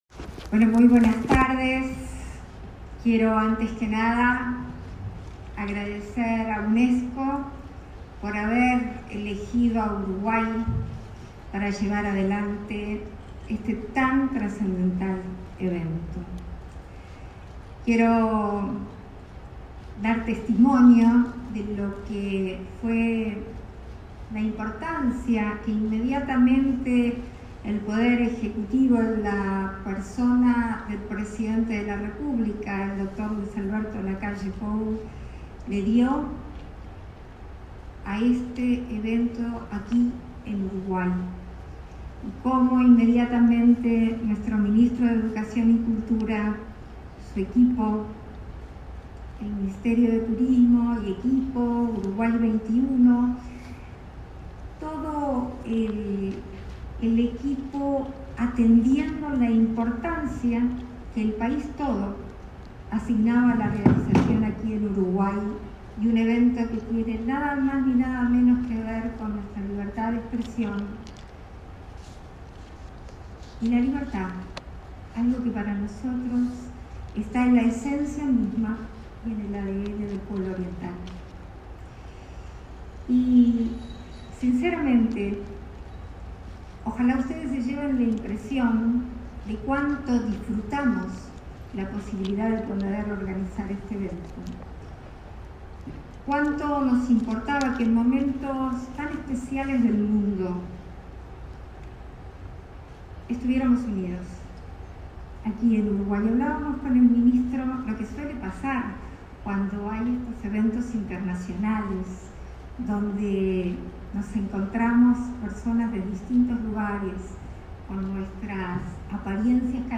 Palabras de la vicepresidenta de la República, Beatriz Argimón
Palabras de la vicepresidenta de la República, Beatriz Argimón 04/05/2022 Compartir Facebook Twitter Copiar enlace WhatsApp LinkedIn La vicepresidenta de la República, Beatriz Argimón, participó, este miércoles 4 en Punta del Este, en la sesión plenaria de clausura de las actividades desarrolladas en el marco del Día Internacional de la Libertad de Prensa.